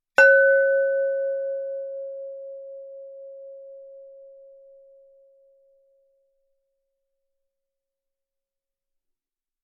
Glass Lid
bell ding glass lid percussion ring sound effect free sound royalty free Sound Effects